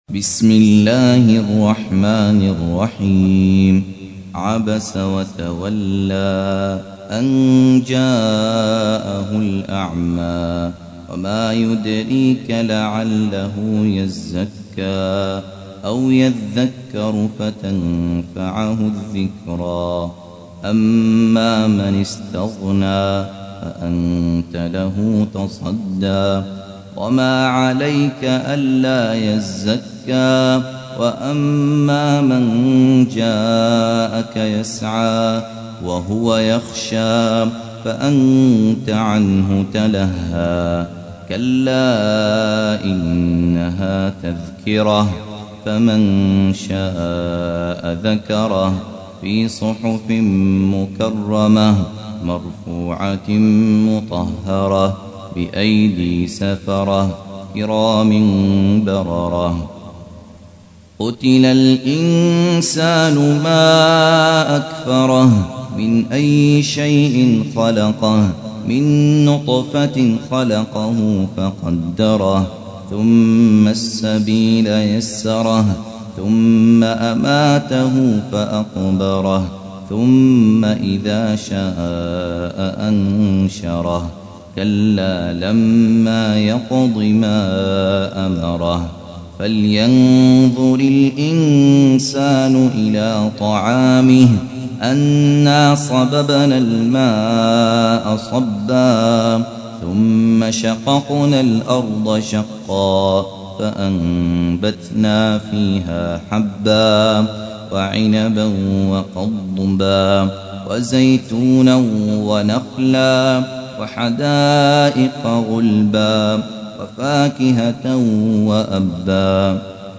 80. سورة عبس / القارئ